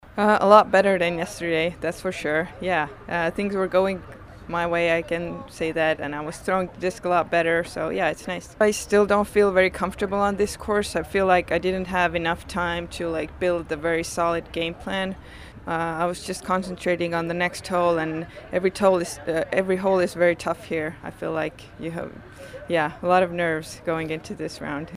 The 3 leaders talked with the Disc Golf Network after the second round.